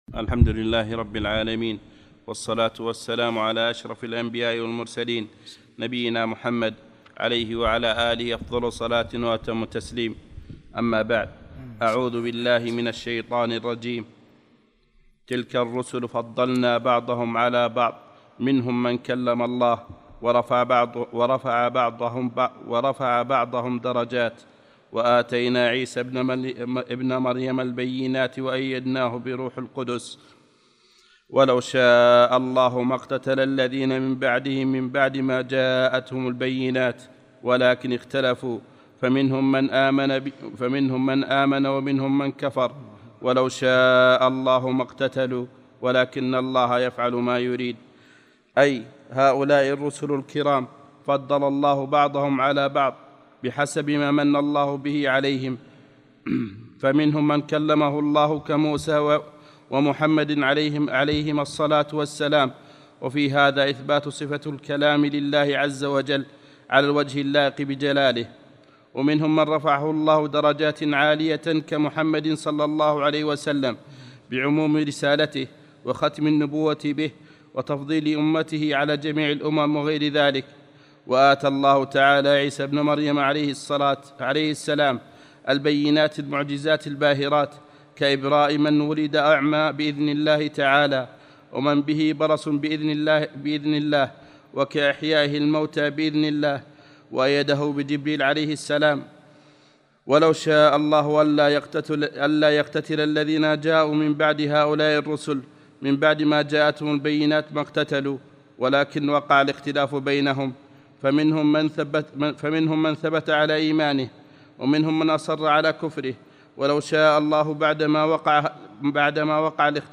34 - الدرس الرابع والثلاثون